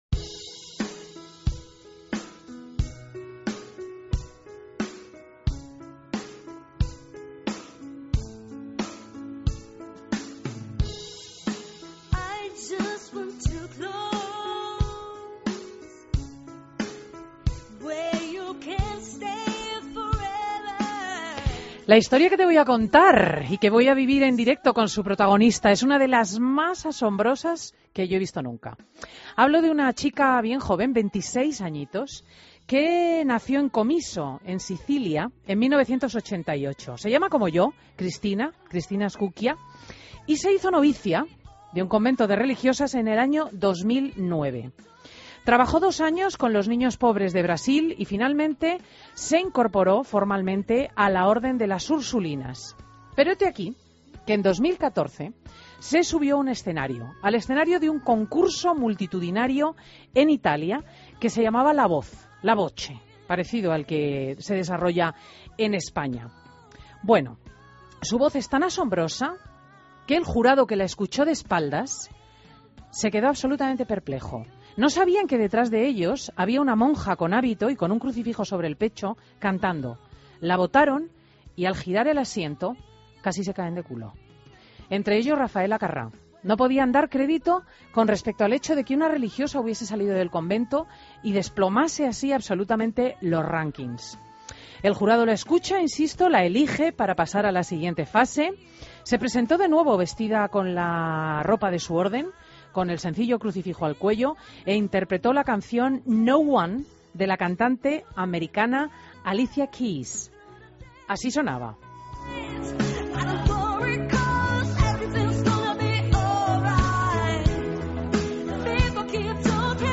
Entrevista a Sor Cristina Scuccia en Fin de Semana COPE